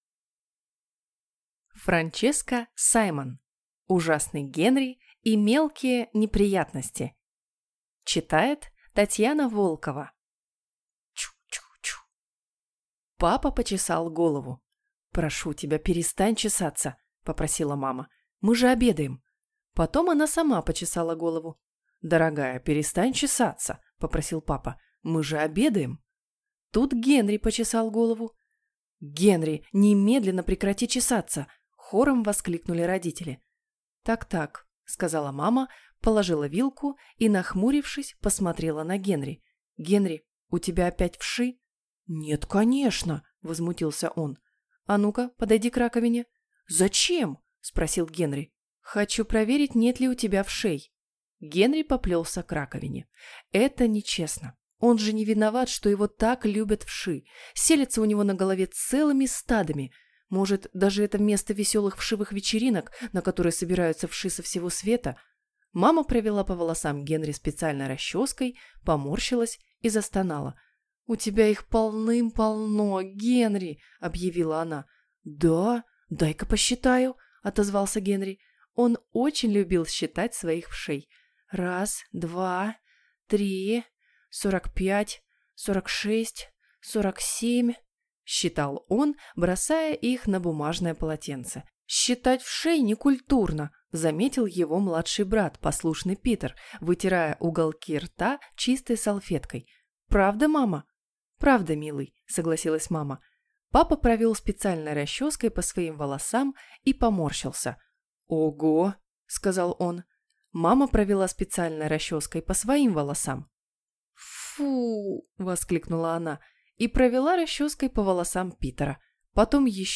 Аудиокнига Ужасный Генри и мелкие неприятности | Библиотека аудиокниг